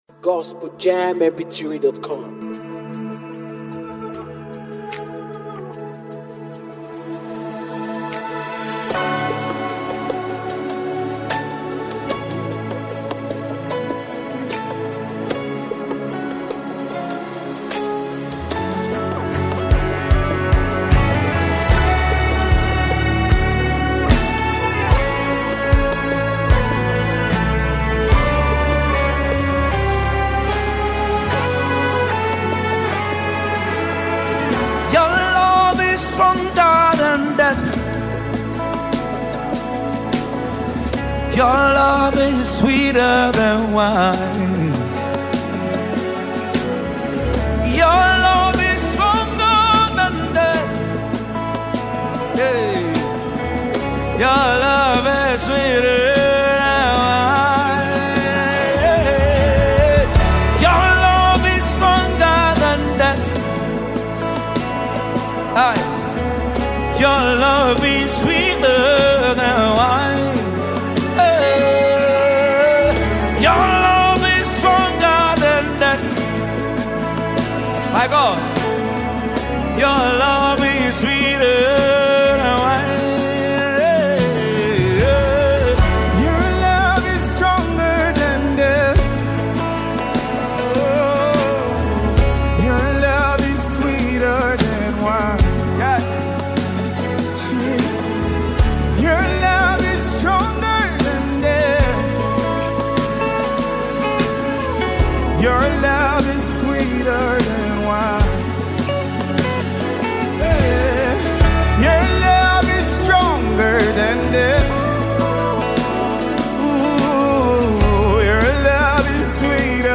is a deeply reverent gospel worship song
With passionate vocals and gentle instrumentation